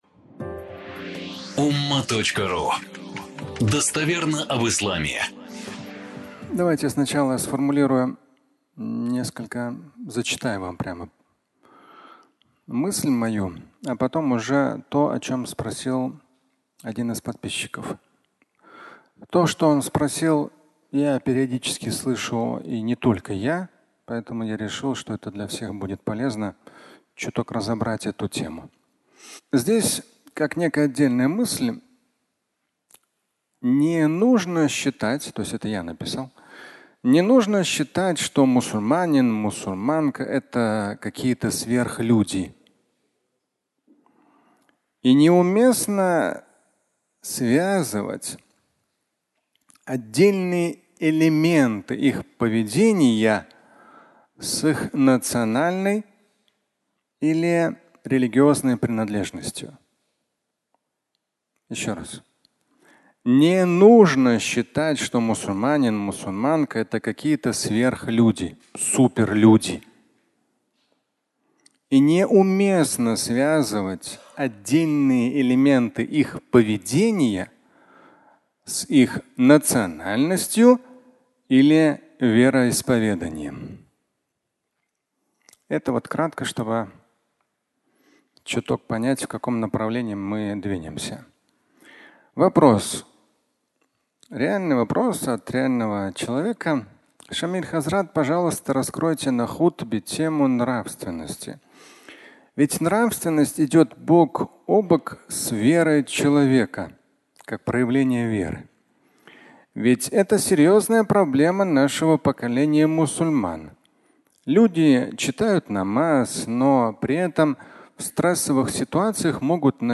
Суперчеловек (аудиолекция)